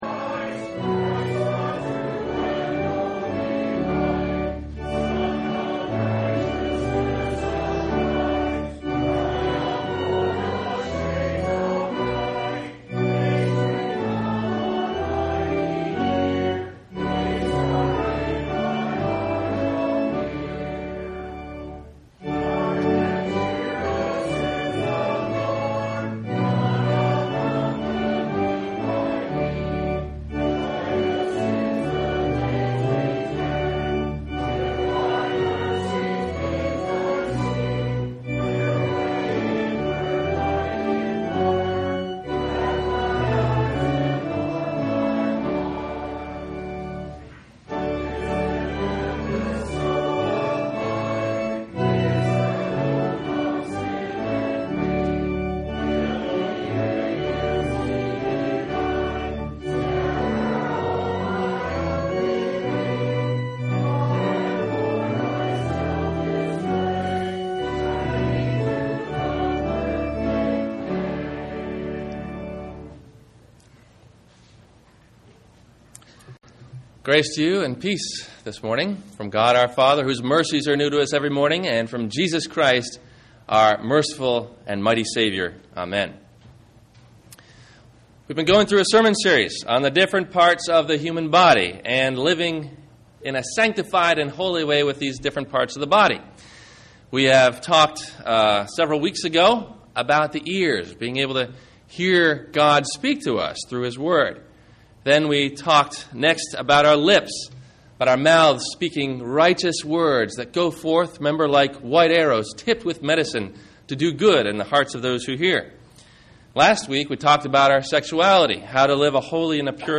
The Eyes of Faith – Sermon – February 15 2009